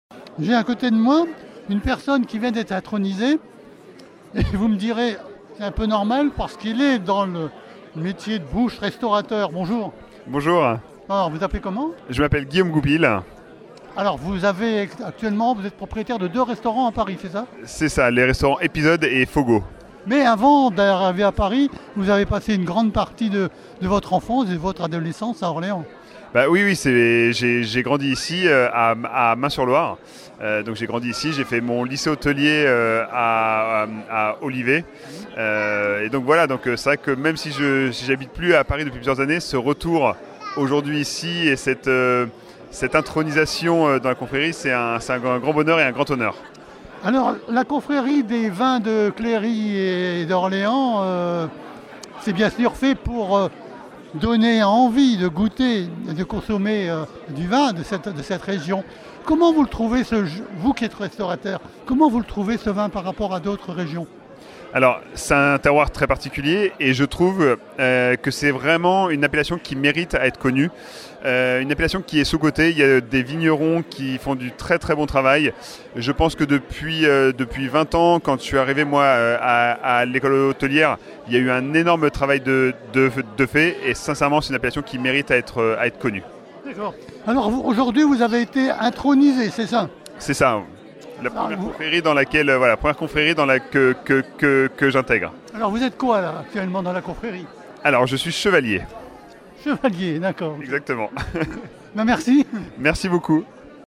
VAG MUSIC-CONFRERIES - INTERVIEW NO 6